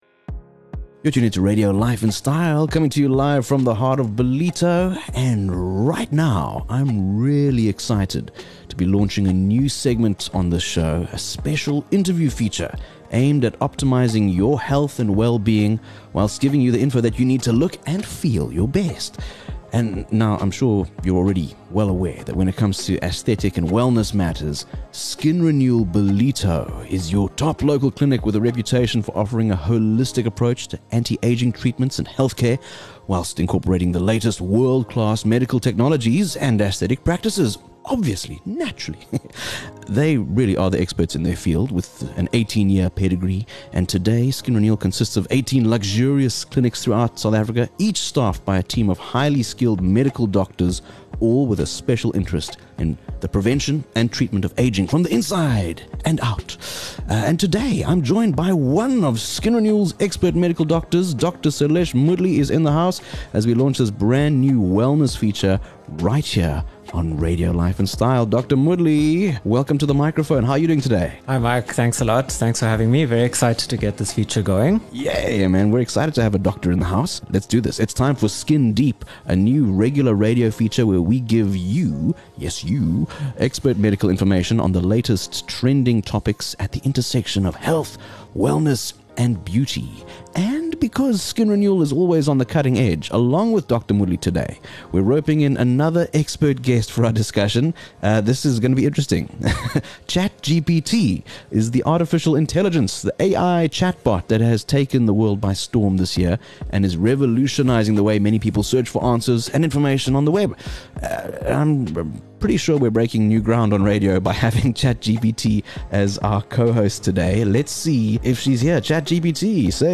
(the AI chatbot that has been given a speaking voice for the show through the wonders of text-to-speech technology.)